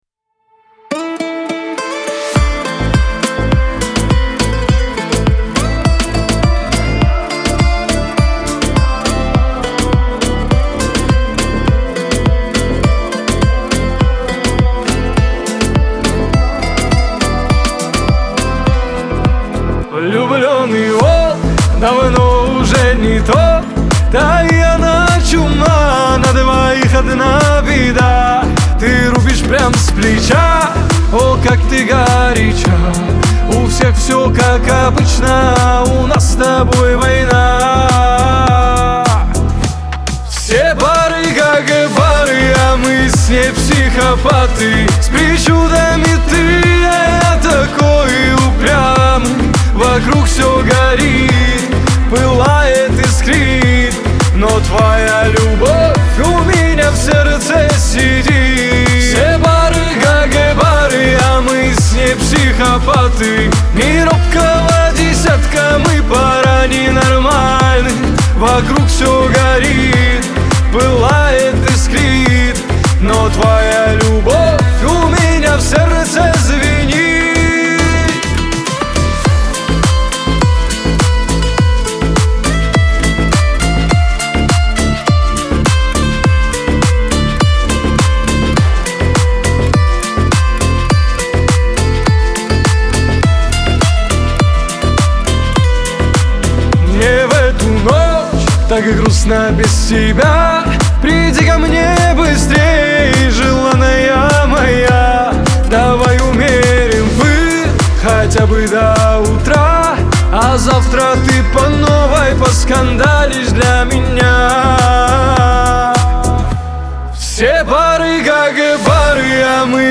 Лирика
Кавказ поп